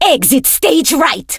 diva_kill_vo_02.ogg